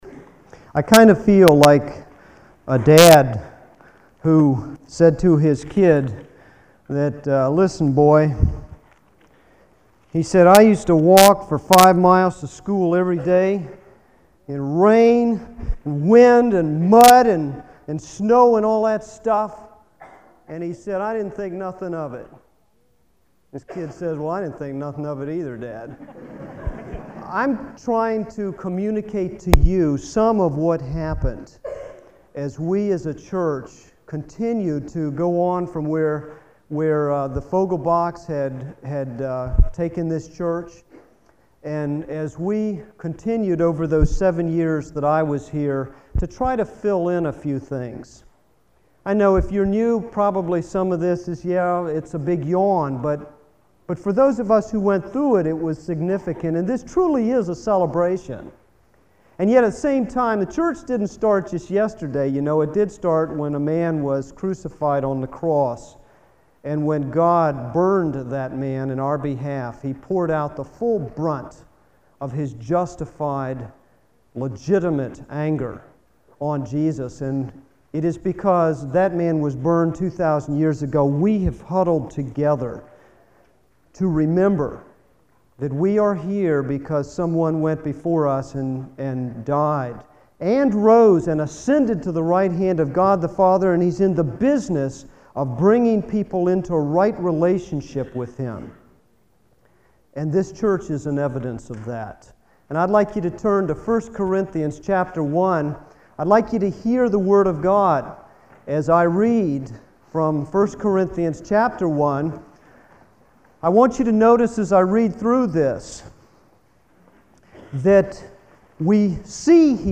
MP3 podcast audio files of the latest sermons from Brick Lane Community Church.